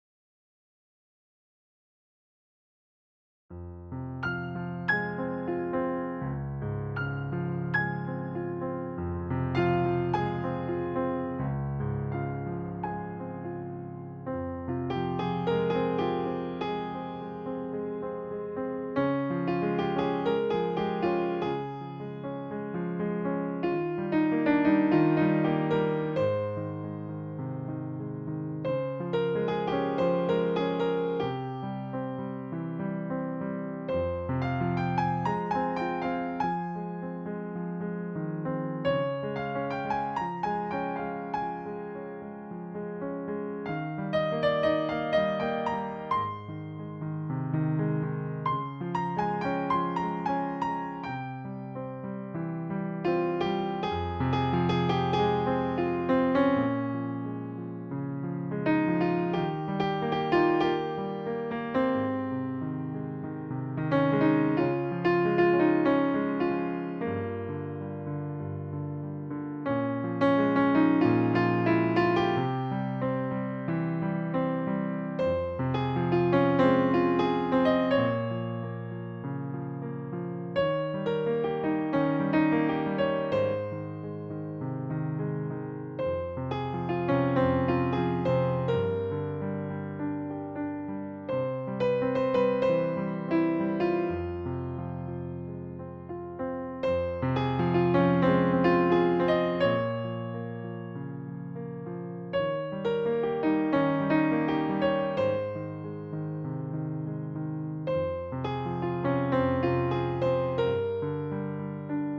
балада